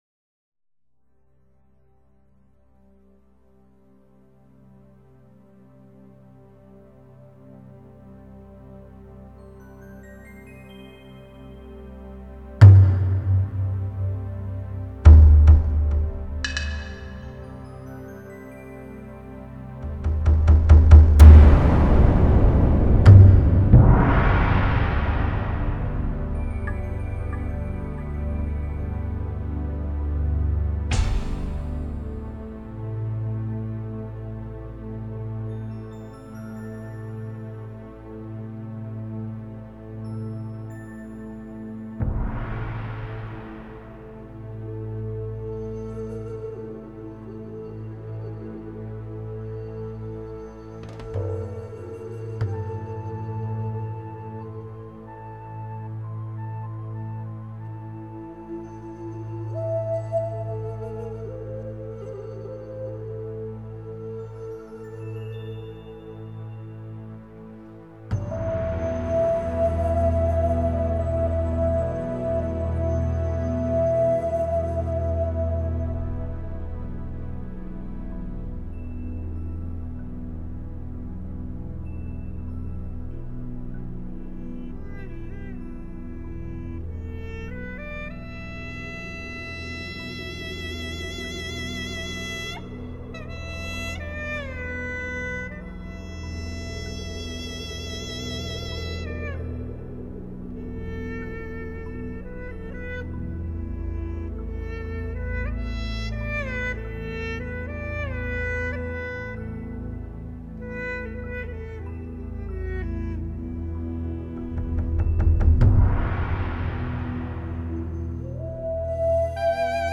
本辑是青海民歌改编的轻音乐。中国民族乐器为主奏，辅以西洋管弦乐器及电声乐器。
最新数码系统录制，堪称民乐天碟。